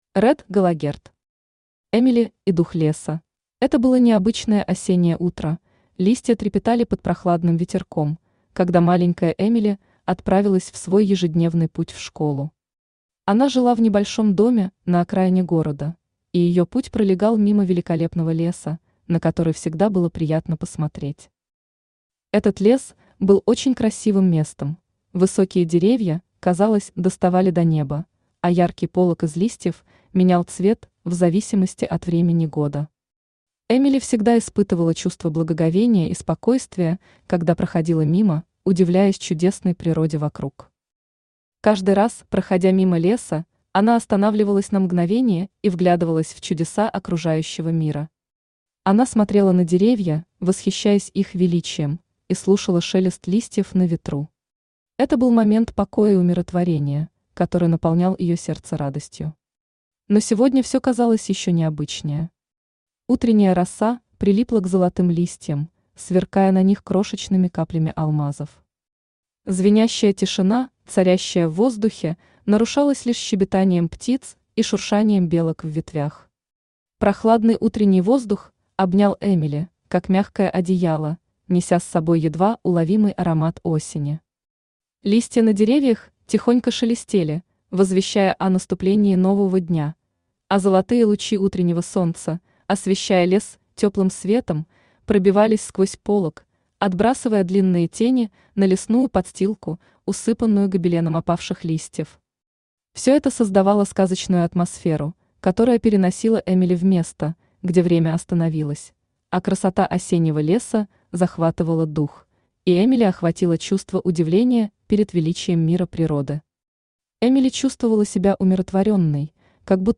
Аудиокнига Эмили и дух леса | Библиотека аудиокниг
Aудиокнига Эмили и дух леса Автор Ред Галогерт Читает аудиокнигу Авточтец ЛитРес.